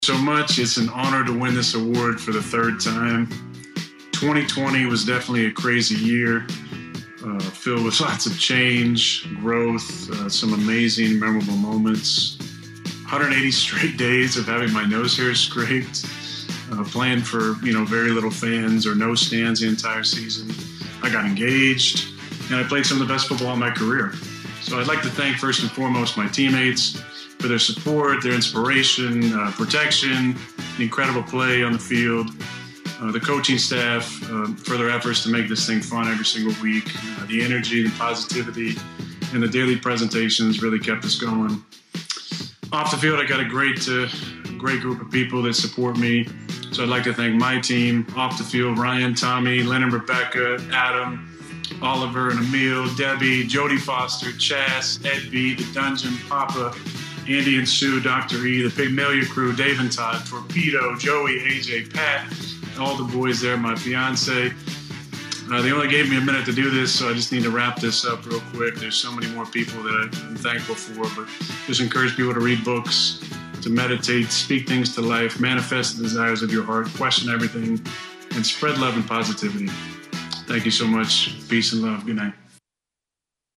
The honors program is typically held in a lavish auditorium, but not this year as Rodgers, donning a t-shirt, delivered his acceptance speech via video:
rodgers-mvp-accept-2-6.mp3